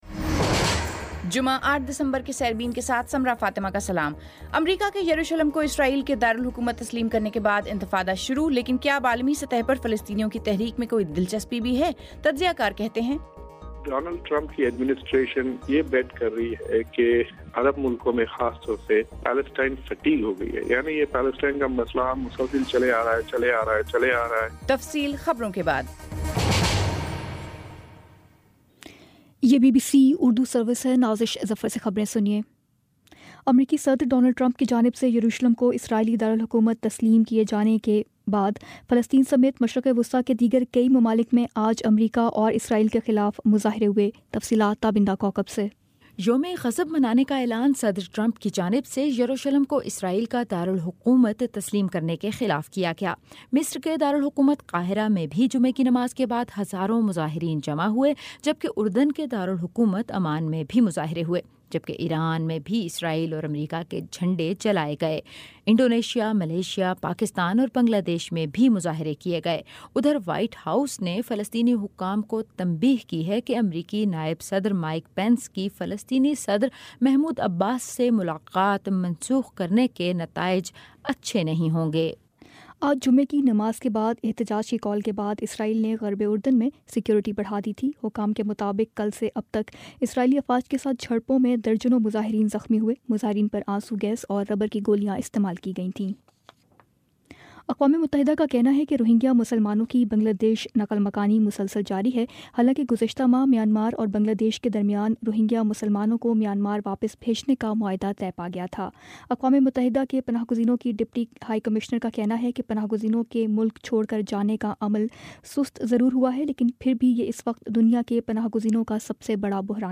جمعہ 08 دسمبر کا سیربین ریڈیو پروگرام